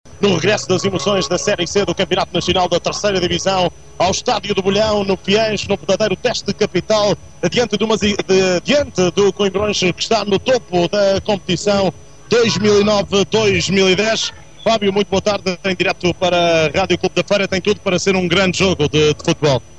Energetic and fresh Portuguese Voice with different registers.
Sprechprobe: eLearning (Muttersprache):
Professional Portuguese VO talent.